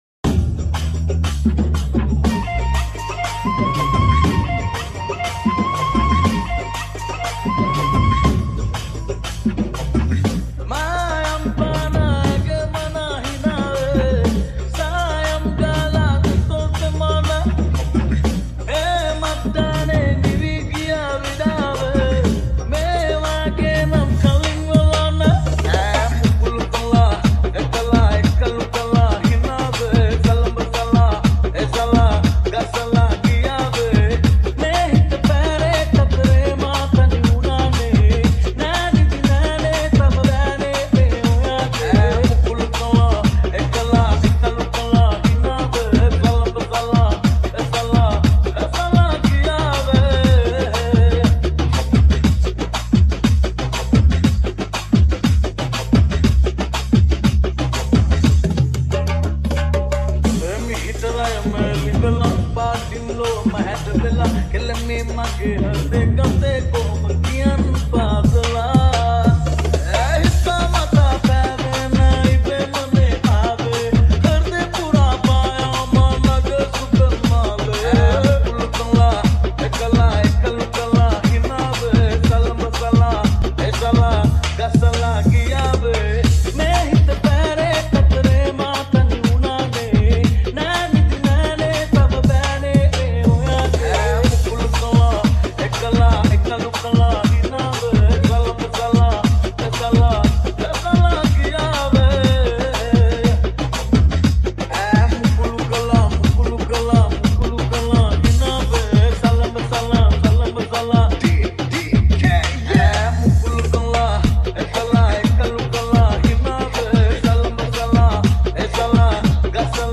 Sinhala Dj Remix